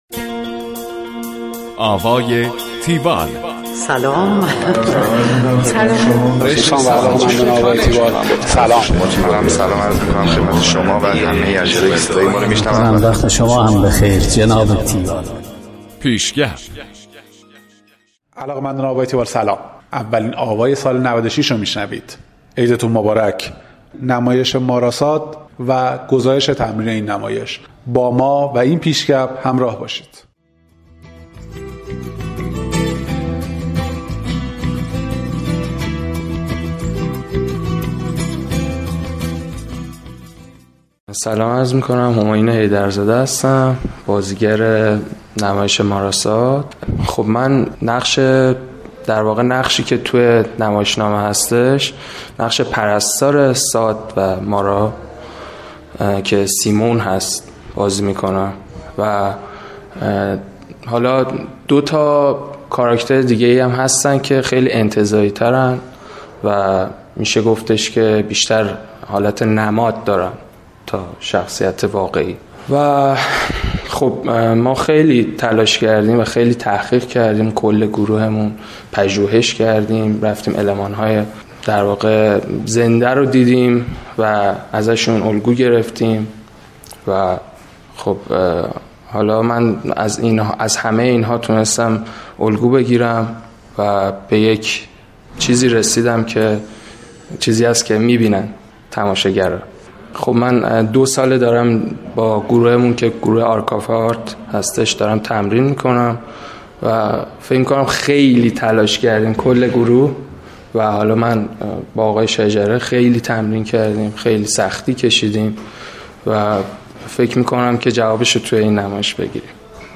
گزارش آوای تیوال از نمایش ماراساد